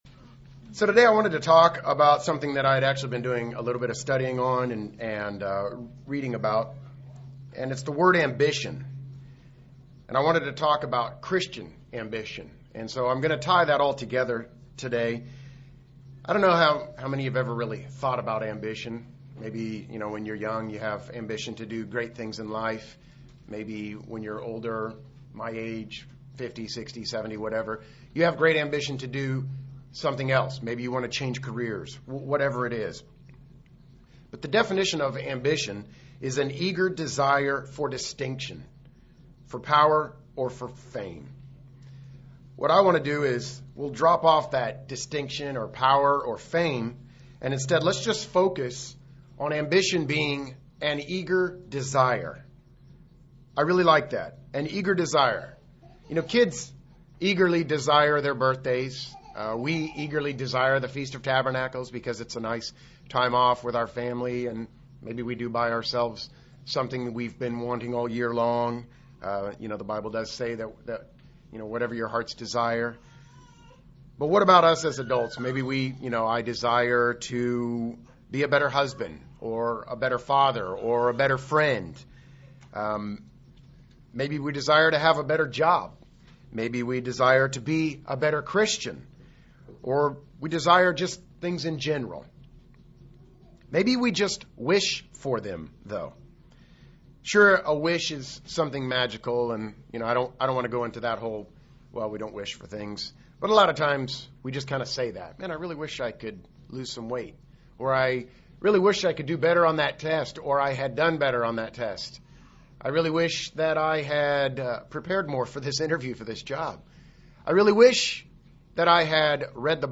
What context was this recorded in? Given in Lawton, OK